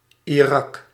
Ääntäminen
IPA : /ɪˈɹɑːk/